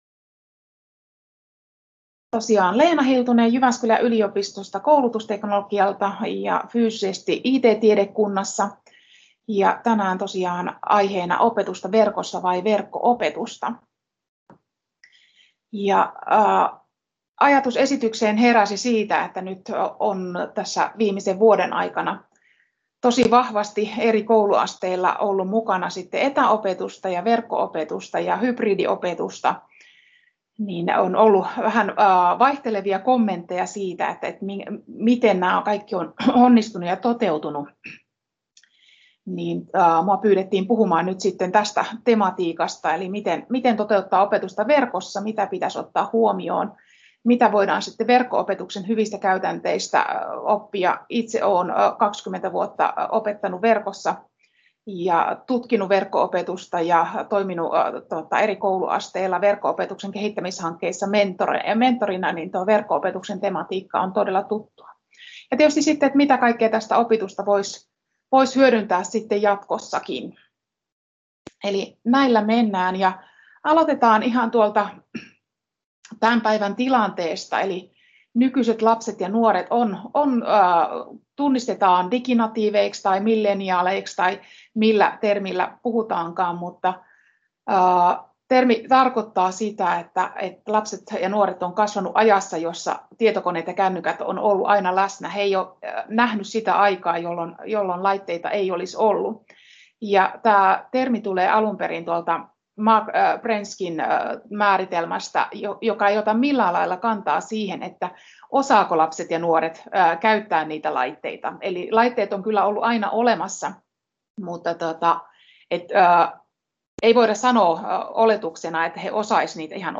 esitys eTUTORE-tapahtumassa 11.2.2021